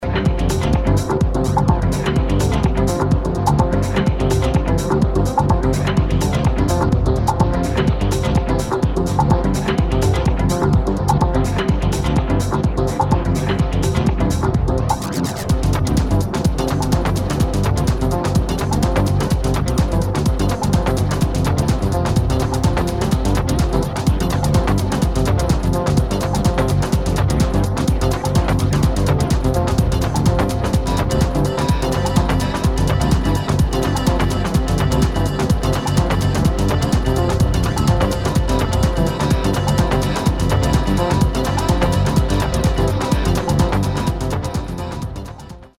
[ TECHNO / ACID / TRANCE ]